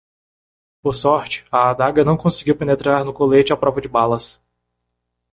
Ler máis (Inglés) Noun Verb coletar to collect, gather Ler máis Frecuencia C1 Pronúnciase como (IPA) /koˈle.t͡ʃi/ Etimoloxía (Inglés) Tomado de francés collet In summary Borrowed from French collet, from Italian colletto.